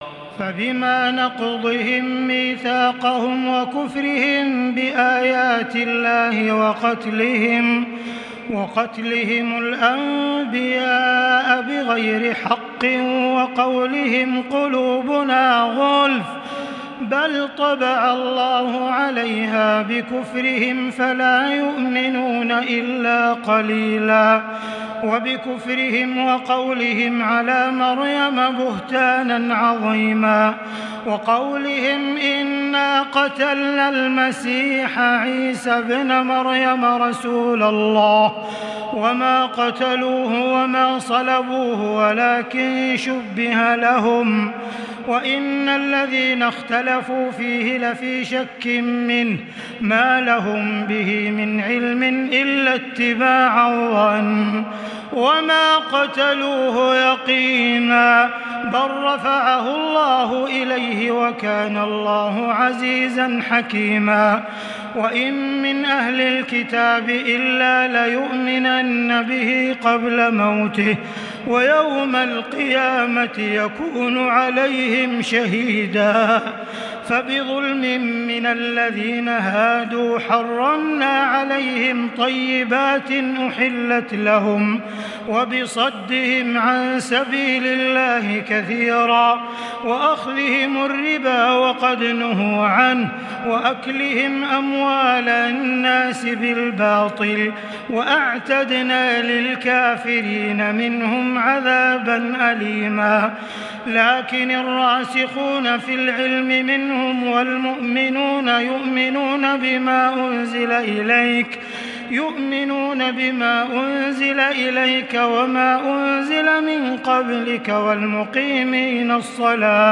تراويح الليلة الخامسة رمضان 1440هـ من سورتي النساء (155-176) و المائدة (1-40) Taraweeh 5 st night Ramadan 1440H from Surah An-Nisaa and AlMa'idah > تراويح الحرم المكي عام 1440 🕋 > التراويح - تلاوات الحرمين